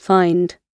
find-gb.mp3